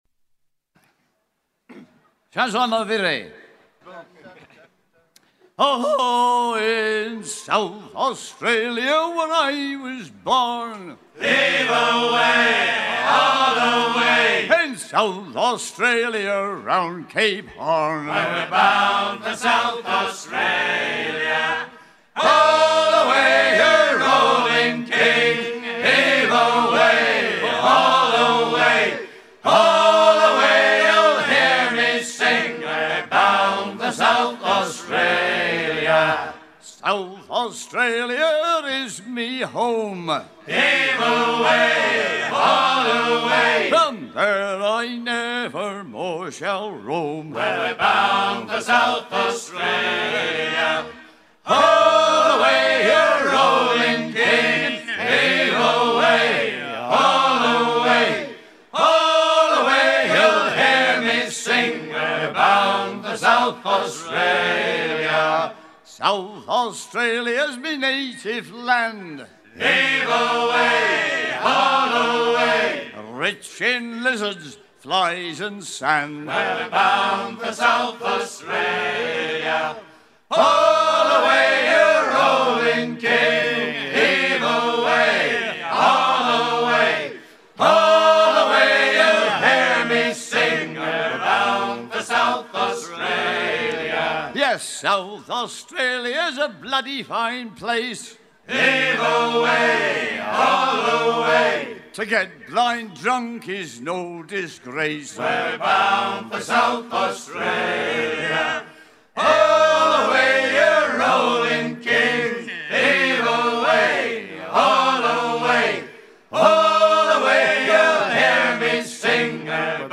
South Australia Détails de l'archive Titre South Australia Origine du titre : Editeur Note shanty dont le nombre de coulet variat puisque improvisé au fil de l'action.
Pièce musicale éditée